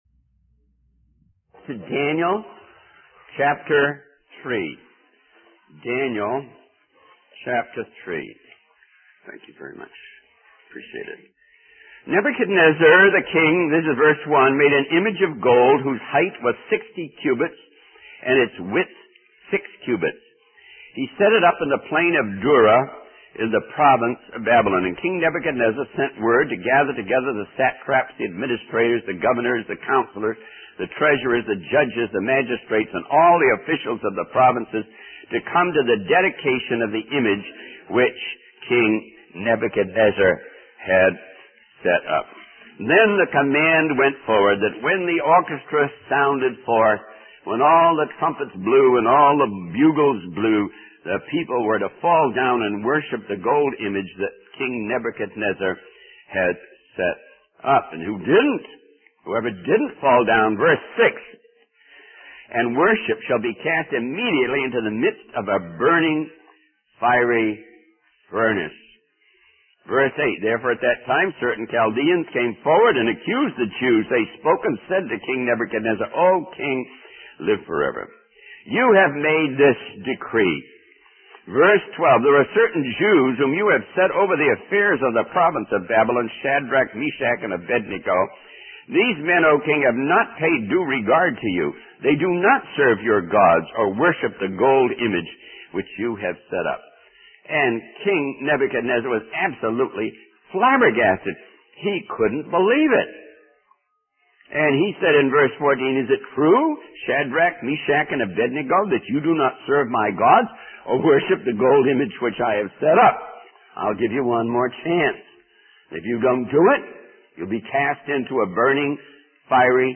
In this sermon, the preacher focuses on the story of Shadrach, Meshach, and Abednego from the book of Daniel.